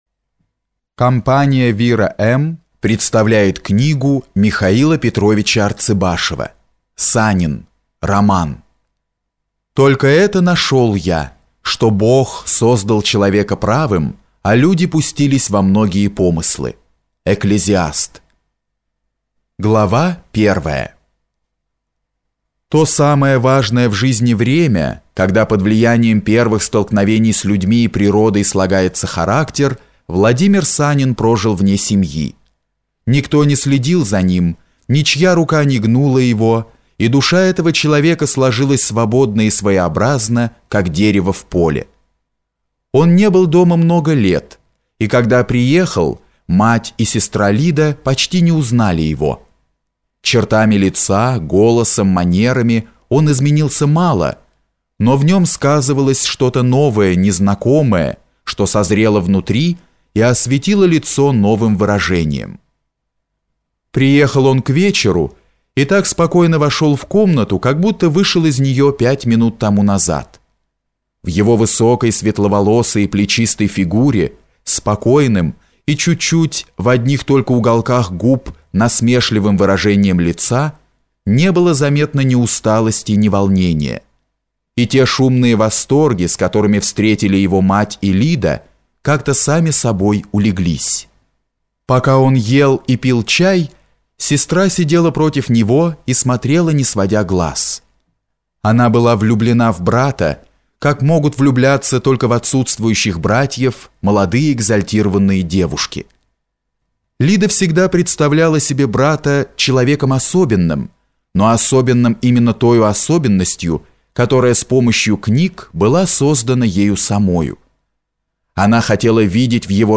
Аудиокнига Санин | Библиотека аудиокниг